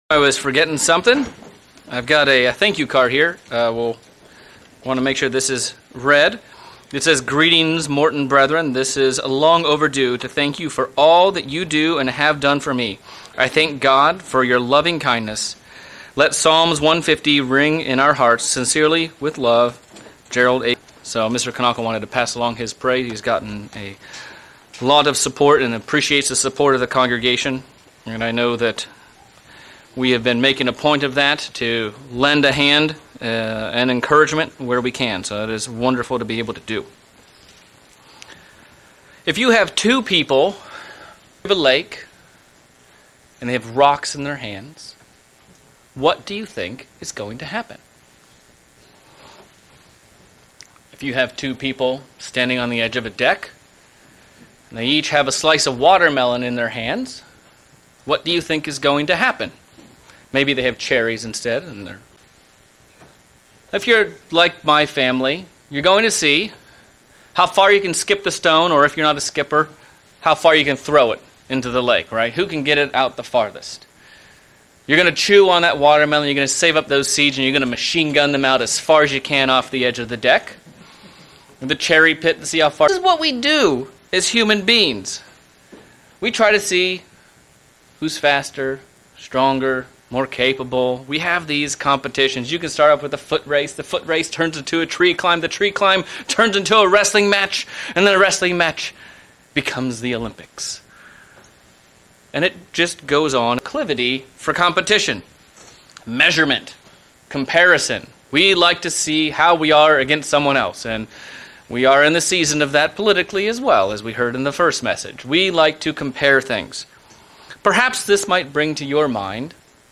This sermon covers 3 areas of growth for the Christian A. Get rid of our aversion to yardsticks. God has a standard that we should work to measure up to.